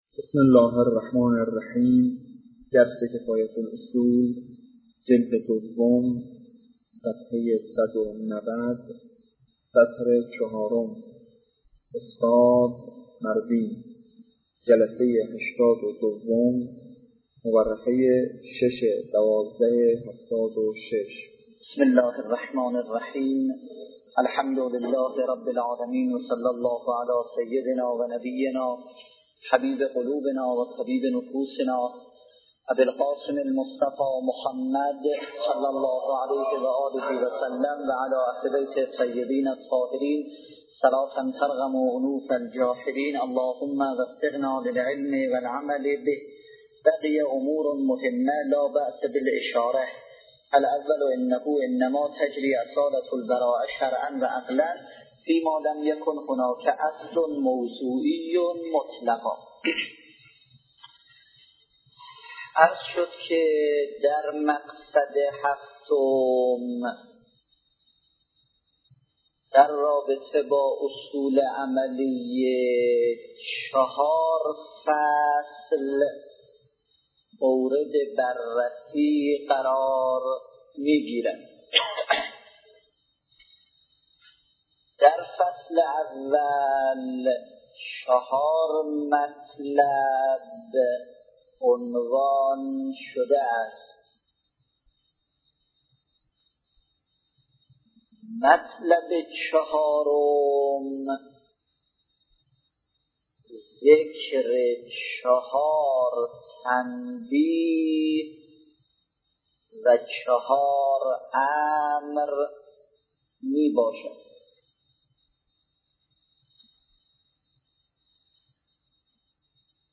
نیوایج